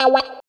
136 GTR 4 -R.wav